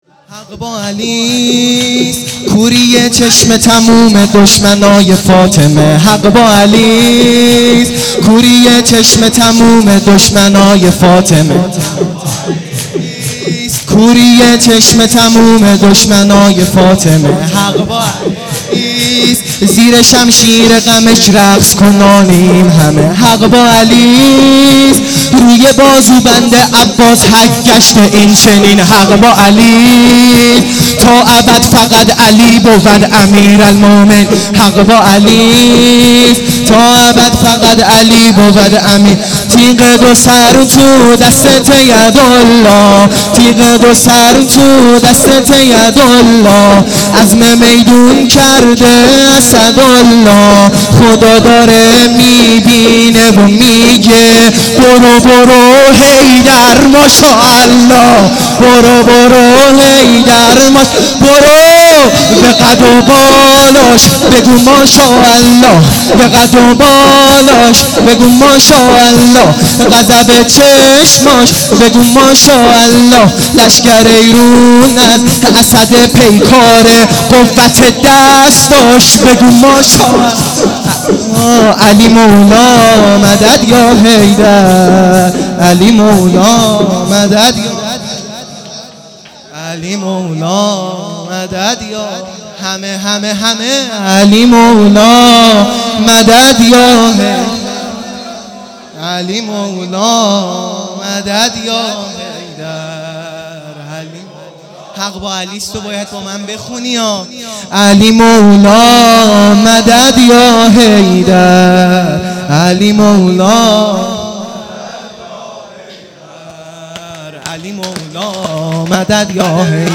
شور
شب نوزدهم رمضان